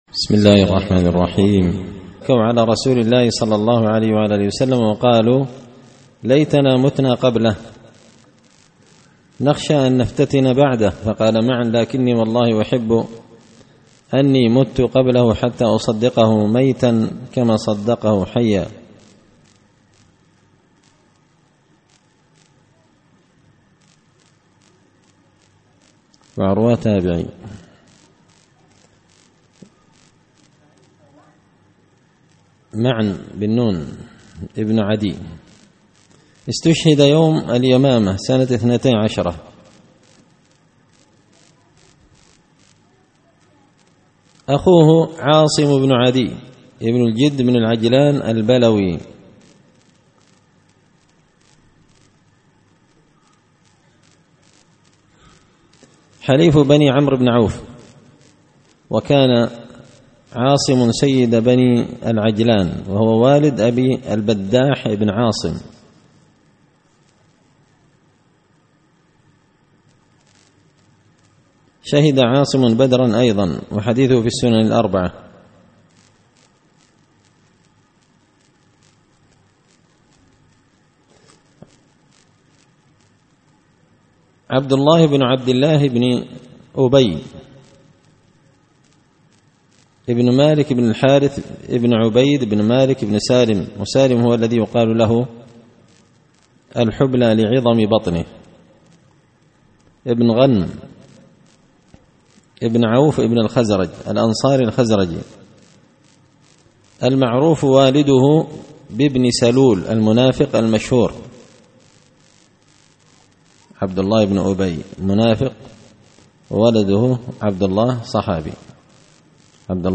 دار الحديث بمسجد الفرقان ـ قشن ـ المهرة ـ اليمن